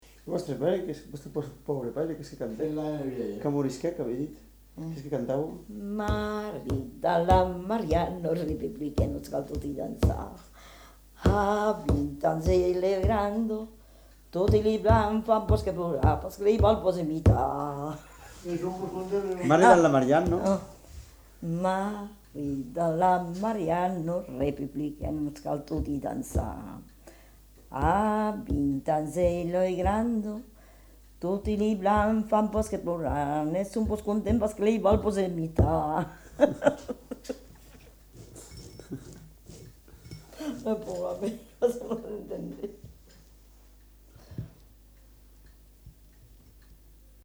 Aire culturelle : Lauragais
Lieu : Lanta
Genre : chant
Effectif : 1
Type de voix : voix de femme
Production du son : chanté